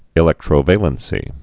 (ĭ-lĕktrō-vālən-sē)